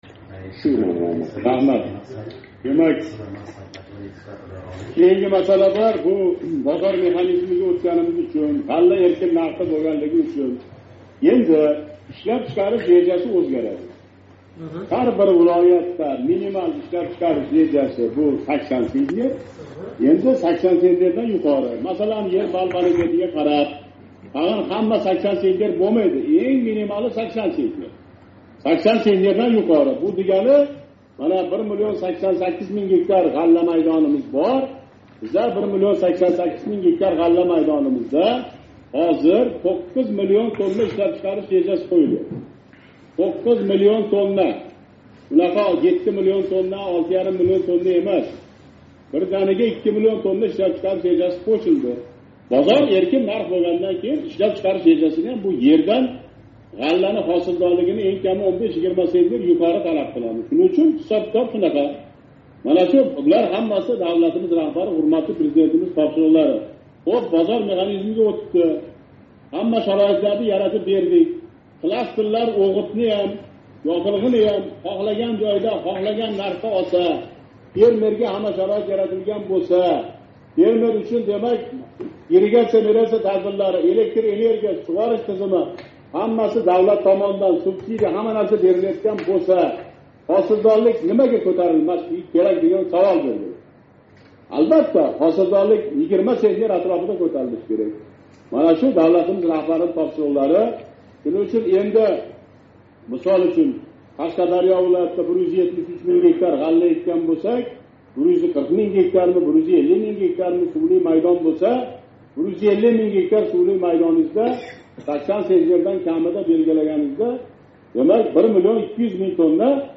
Ўзбекистон Бош вазирининг аграр ва озиқ-овқат соҳаларини ривожлантириш масалалари бўйича ўринбосари Шуҳрат Ғаниев 17 ноябрда ўтказган селектор йиғилишида, республика ғаллакорлари олдига ҳар гектар ердан камида 80 центнердан буғдой етиштириш вазифасини қўйди.
Бош вазир ўринбосари Шуҳрат Ғаниевнинг селектор йиғилишида берган топшириғига кўра, 2022 йилда ғалла етиштиришни 2 миллион тоннага ошириш керак: